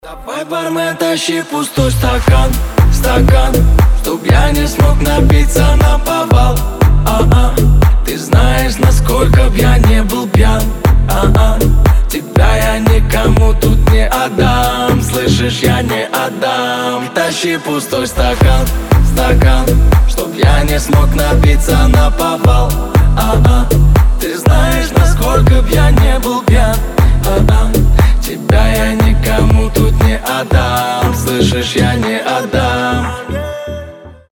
• Качество: 320, Stereo
поп
ритмичные
приятный мужской голос